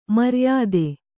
♪ maryāde